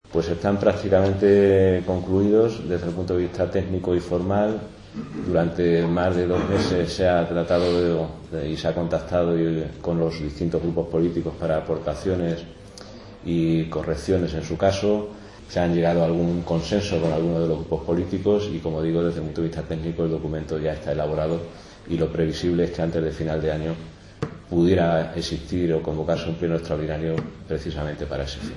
• Así lo ha avanzado el portavoz de Gobierno, Francisco Delgado, que ha detallado el orden del día del pleno ordinario del mes de diciembre.
Delgado detallaba como avanzan los presupuestos del próximo ejercicio a preguntas de los medios de comunicación, durante la presentación del orden del día del Pleno ordinario del mes de diciembre.